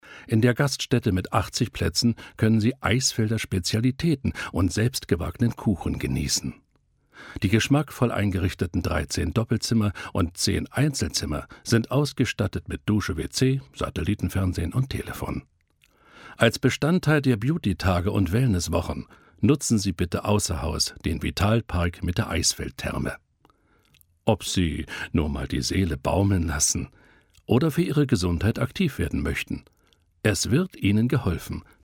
tiefe Stimme=WERBUNG:Bier,Bundeswehr, Ergo,Stadt München-DRAMA:Hörbuch, Kirchenlyrik-DOK:Jüdi. Museum-Leitstimme-COMIC:Paradiso-
Sprechprobe: Werbung (Muttersprache):